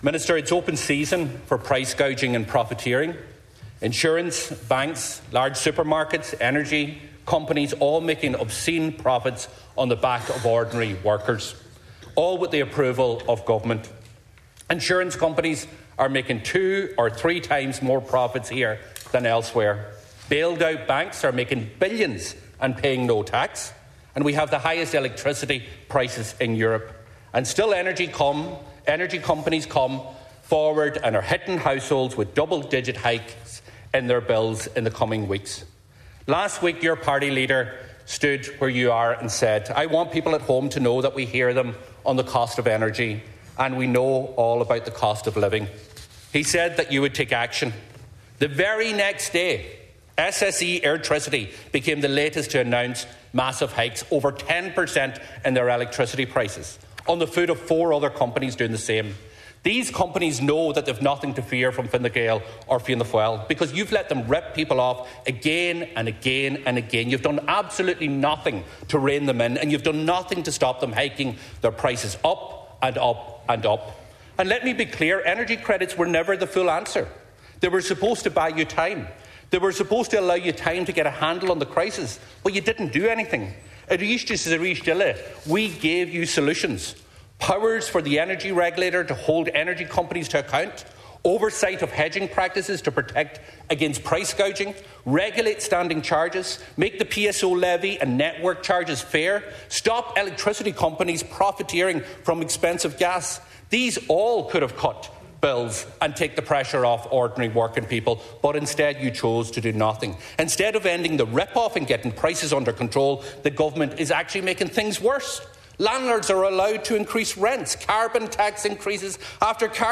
The Sinn Fein Finance Spokesperson says solutions to the energy cost crisis are available:
pearse-dail-web.mp3